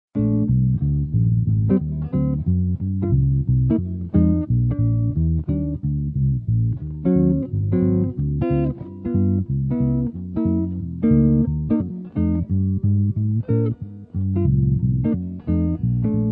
My approach was to install a Copeland 6DC+ hex pickup in the neck position of my Ibanez AR250.
The bass-sounding strings two are sent through a Danelectro Chili Dog octave pedal and a SansAmp BassDriver DI to make a nice jazz bass sound. The guitar-sounding strings are sent through a Danelectro Dan-O-Matic tuner, a Danelectro Fish N Chips EQ, a Boss RV-3 reverb pedal, and the Fenderizer amp simulator pedal I built. In the split mode you can play live walking bass + chords accompaniments and it sounds like this: (